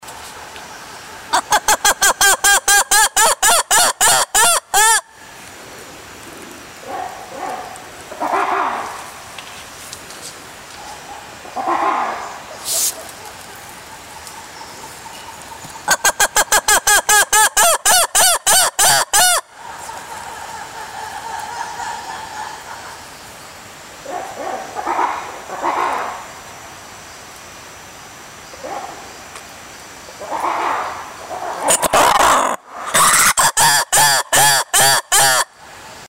Звуки лемуров
Лемуры переговариваются с разных деревьев